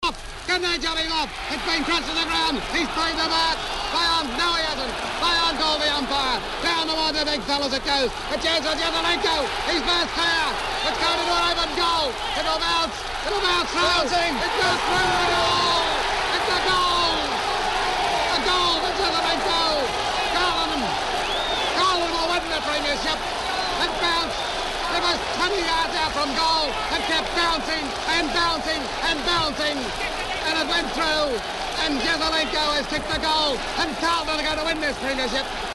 To celebrate famous commentator Harry Beitzel's 90th birthday, here's a throwback to his great call of Alex Jesaulenko's 1970 Grand Final sealer.